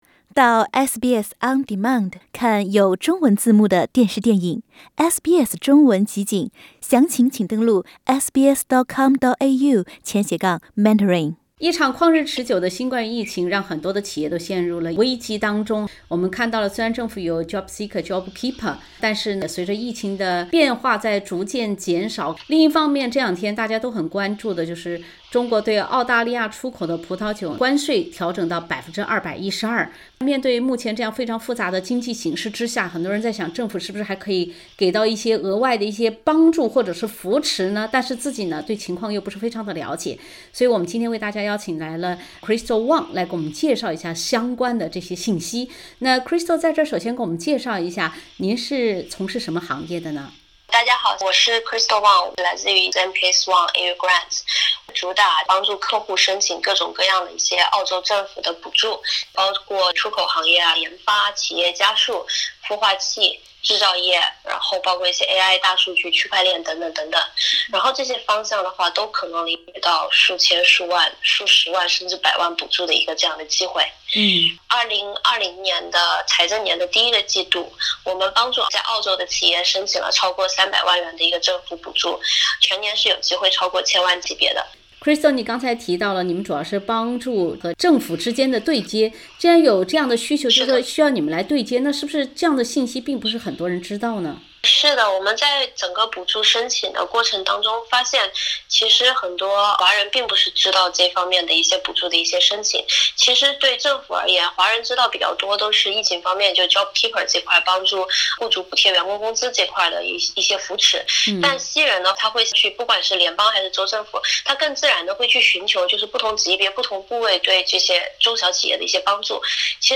欢迎收听本台记者带来的采访报道。